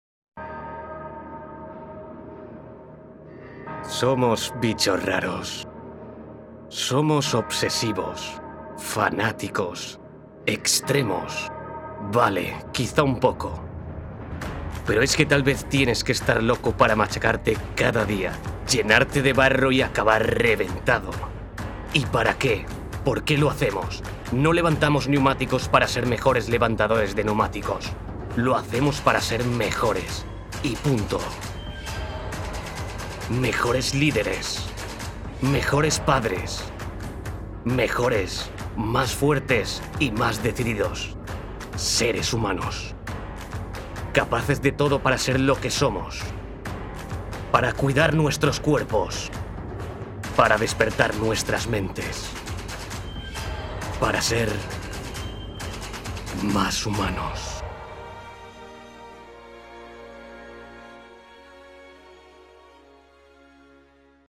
I have mi own home studio in which I do the work in the shortest possible time.
My vocal color is professional, youth, warm, persuasive and friendly.
Sprechprobe: Industrie (Muttersprache):
I am a professional neutral Spanish voice artist.